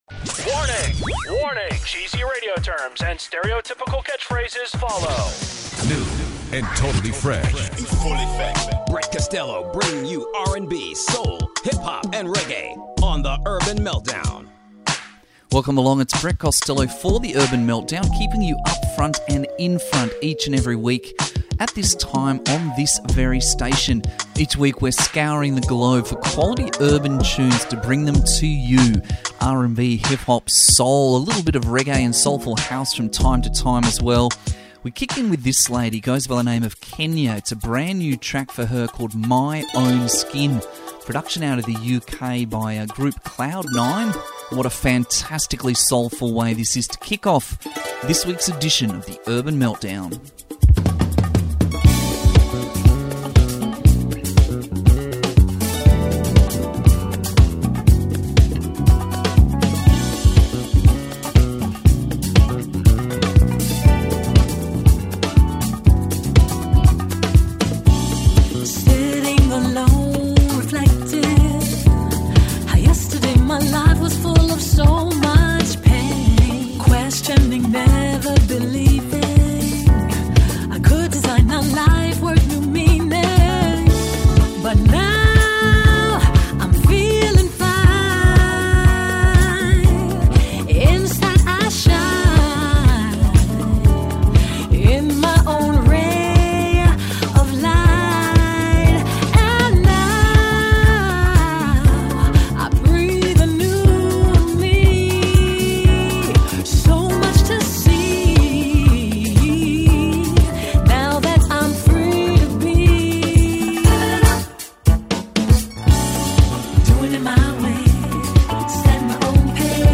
Keeping you upfront and in-front of the latest R&B jams.